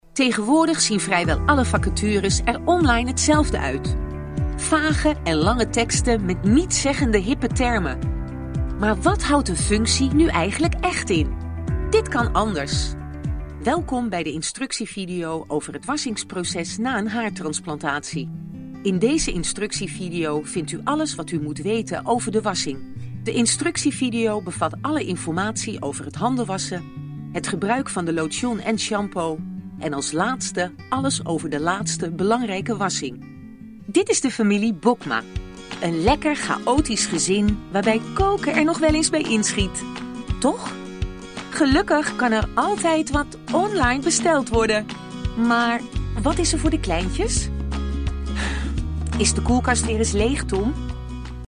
荷兰语中年沉稳 、娓娓道来 、科技感 、积极向上 、时尚活力 、神秘性感 、亲切甜美 、素人 、女专题片 、宣传片 、纪录片 、广告 、飞碟说/MG 、课件PPT 、工程介绍 、绘本故事 、动漫动画游戏影视 、150元/百单词女荷05 荷兰语女声 成熟 沉稳|娓娓道来|科技感|积极向上|时尚活力|神秘性感|亲切甜美|素人
女荷05 荷兰语女声 温婉 沉稳|娓娓道来|科技感|积极向上|时尚活力|神秘性感|亲切甜美|素人
女荷05 荷兰语女声 干音 沉稳|娓娓道来|科技感|积极向上|时尚活力|神秘性感|亲切甜美|素人